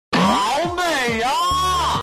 SFX好美啊音效下载
SFX音效